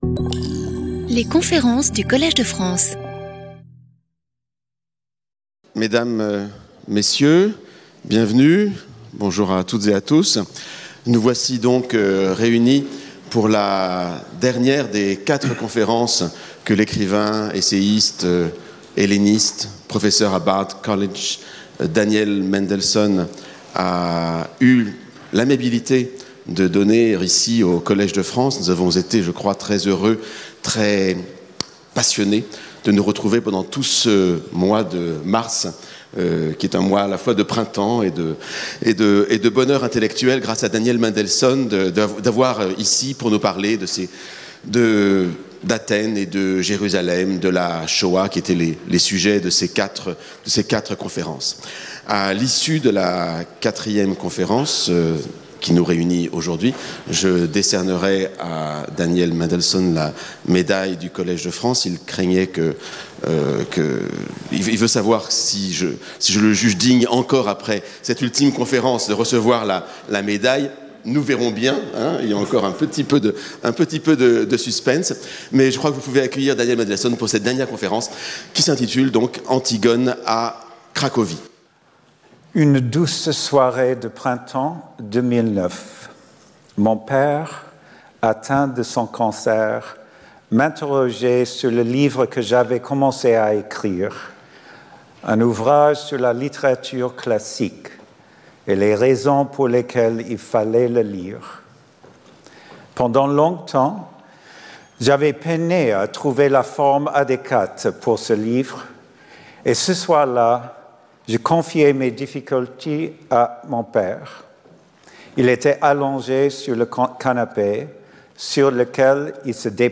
Sauter le player vidéo Youtube Écouter l'audio Télécharger l'audio Lecture audio Résumé Cette conférence est une réflexion sur les processus souvent aléatoires par lesquels la grande littérature du passé classique a été préservée pour le présent – et une réflexion également sur le fait que la plupart des grands classiques n’aient en fait pas survécu.